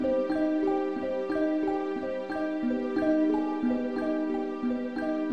描述：90 BPM Bells and plucked style two bar loops I made.
标签： 90 bpm Hip Hop Loops Bells Loops 919.05 KB wav Key : C
声道立体声